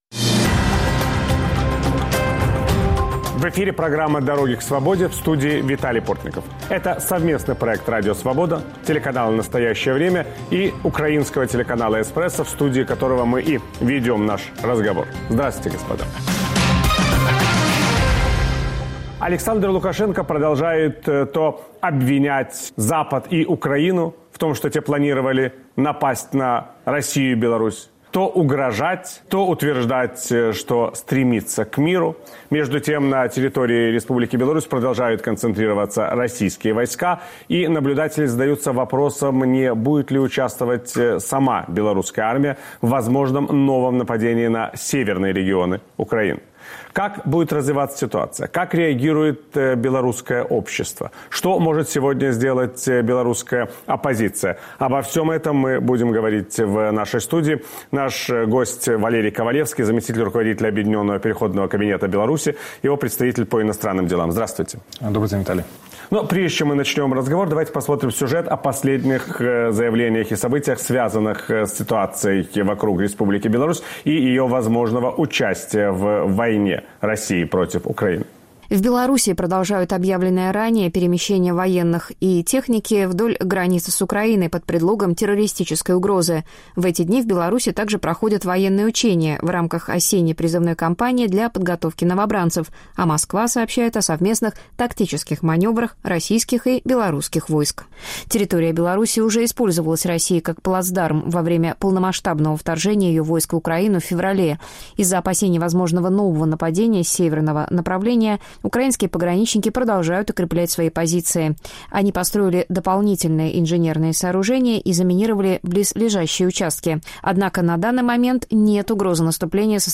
Гость Виталия Портникова - Валерий Ковалевский, заместитель руководителя Объединенного переходного кабинета Беларуси, его представитель по иностранным делам.